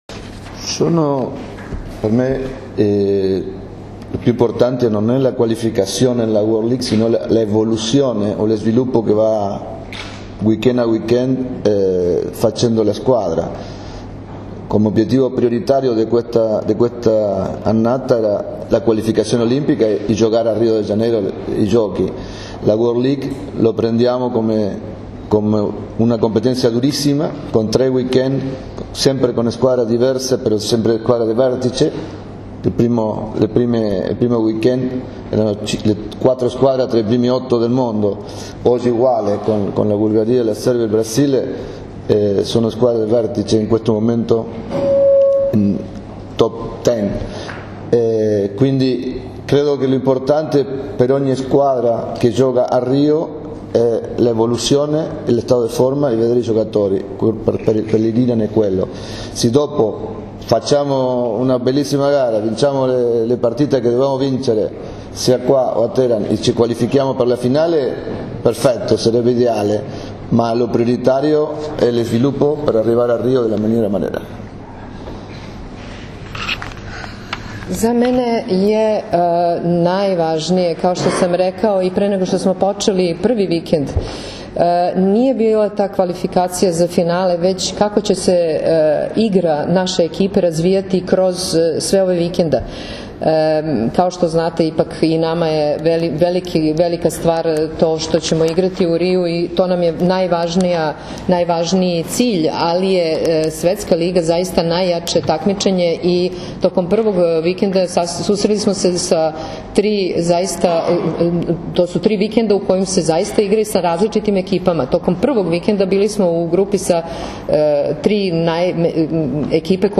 U beogradskom hotelu “M” danas je održana konferencija za novinare povodom turnira F grupe XXVII Svetske lige 2016, koji će se odigrati u dvorani “Aleksandar Nikolić” u Beogradu od 23. – 25. juna.
IZJAVA RAULA LOZANA